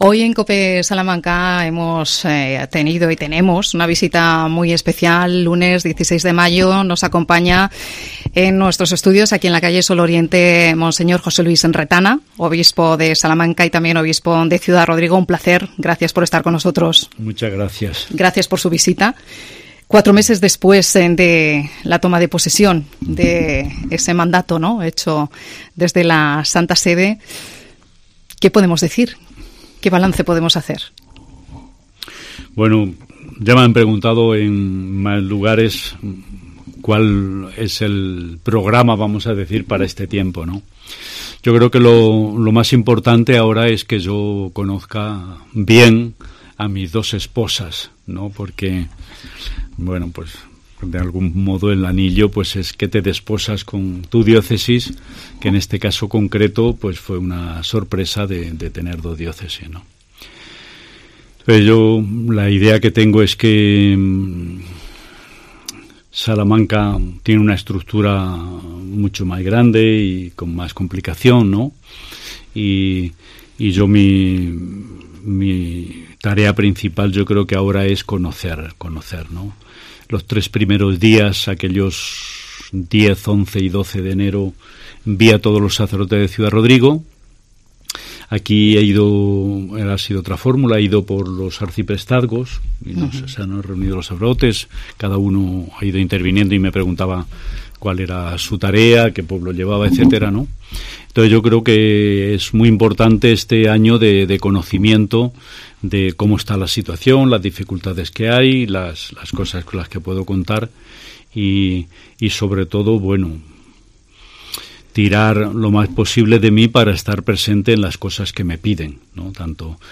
AUDIO: El obispo de Ciudad Rodrigo y Salamanca, don José Luis Retana visita la emisora de COPE. Valora los cuatro meses al frente de las dos diócesis.